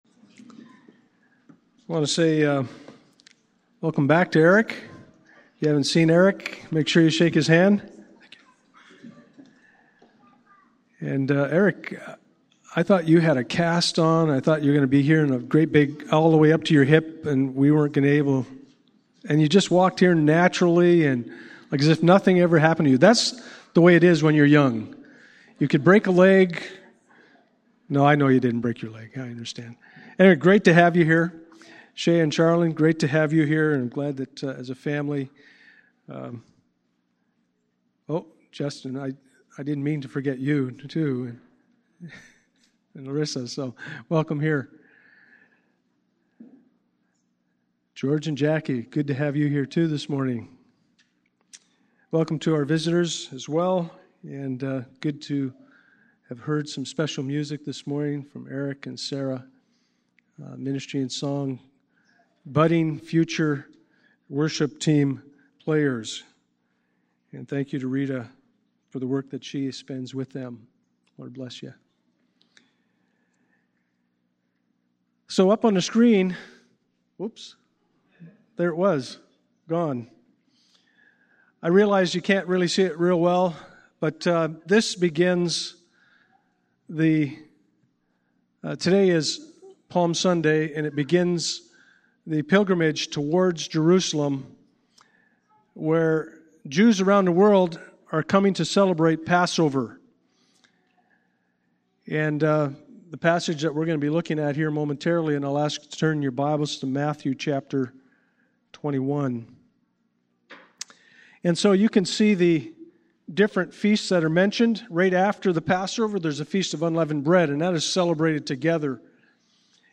Passage: Matthew 21:1-11 Service Type: Sunday Morning « You’re Getting a New Body!!!